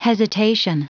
Prononciation du mot hesitation en anglais (fichier audio)
Prononciation du mot : hesitation